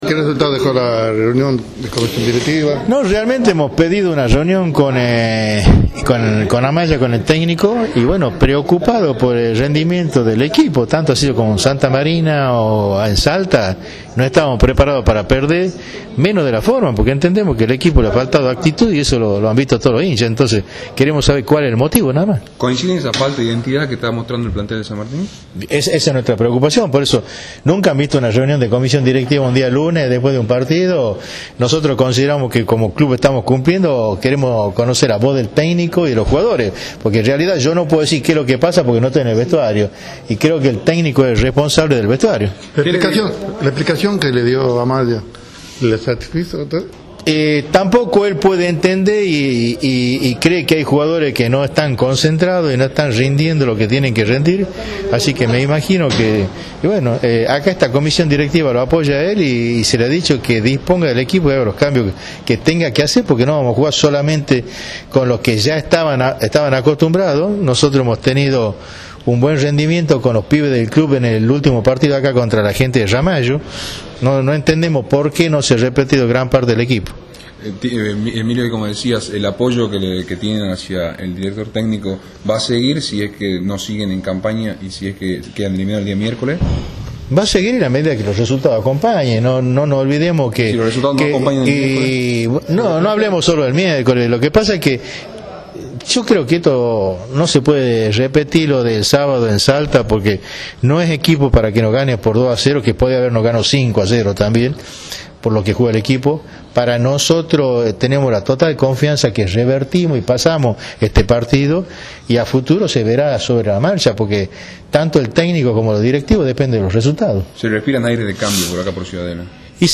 AUDIO - Entrevista completa